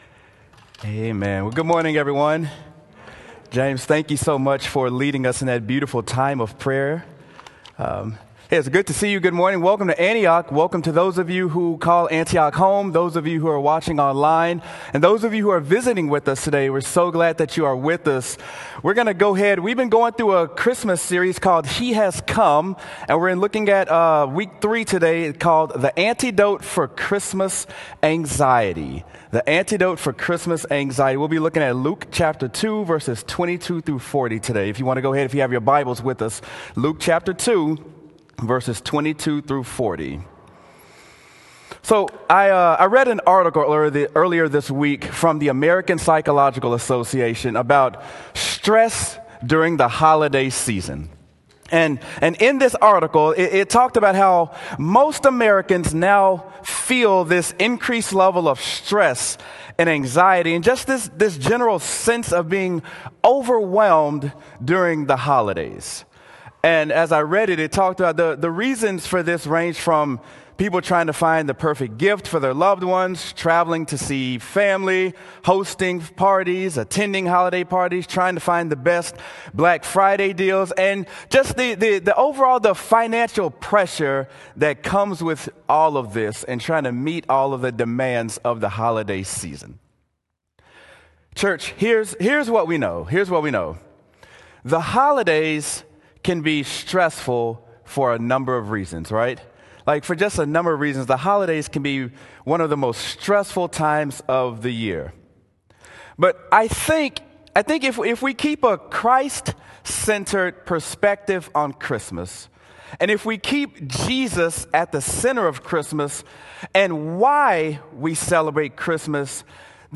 Sermon: He Has Come: The Antidote to Christmas Anxiety